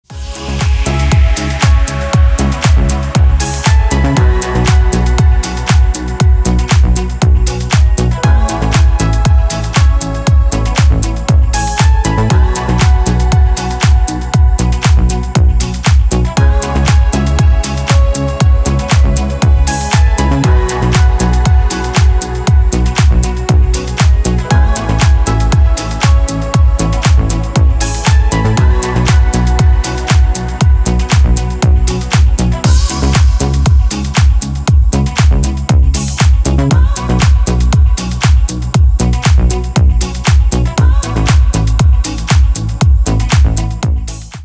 • Категория: Рингтоны
Скачать бесплатно новый рингтон 2024 без слов для мобильного